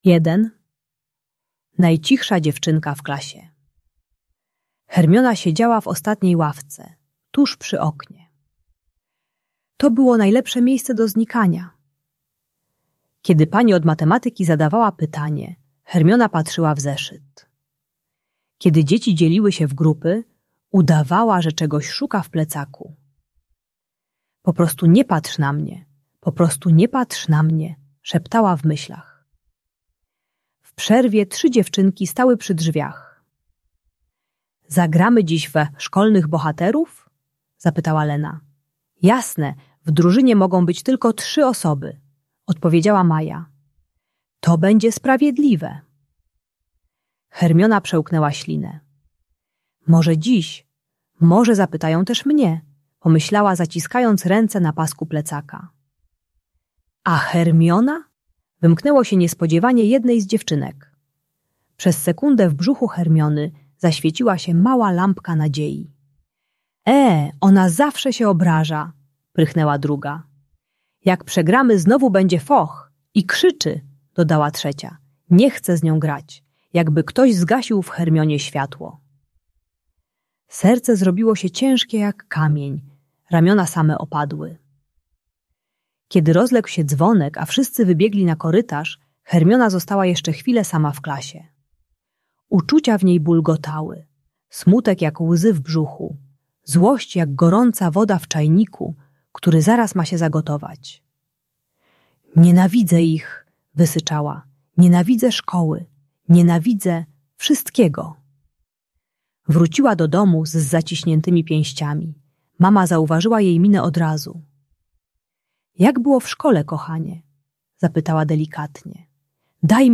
Uczy techniki "3 głębokich oddechów" (Zatrzymanus) - jak zatrzymać złość zanim wybuchnie i nazwać ukryte uczucia pod spodem: smutek, wstyd, samotność. Darmowa audiobajka o złości i odrzuceniu.